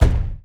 EXPLDsgn_Explosion Impact_02_SFRMS_SCIWPNS.wav